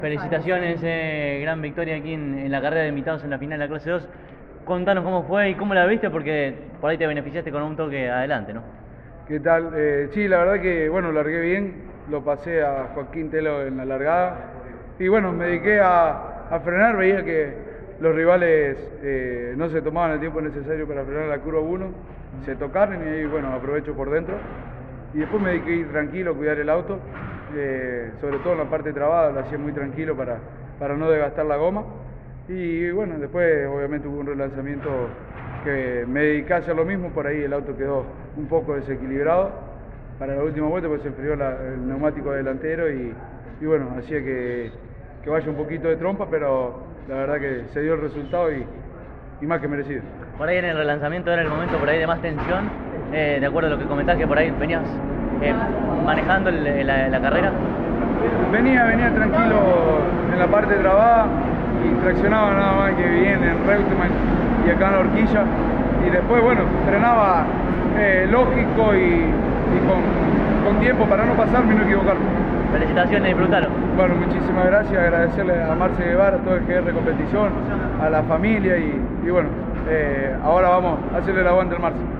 La “Carrera de los 200 Pilotos” del Turismo Nacional en el autódromo de Buenos Aires del fin de semana que acaba de transcurrir, tuvo carreras tanto para los titulares de los autos como para sus invitados y, tras el desarrollo de cada una de las pruebas, los micrófonos de CÓRDOBA COMPETICIÓN tomaron todos los testimonios.